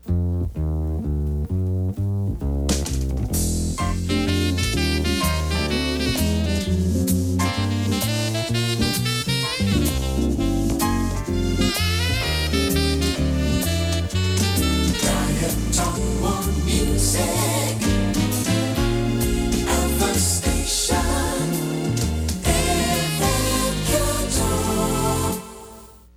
音源は全てステレオ録音です。
全体を通しての感想ですが、ジャズを基調としたとても贅沢な楽曲となっています。
(音声) No2 ジャジーでめちゃカッコイイSJ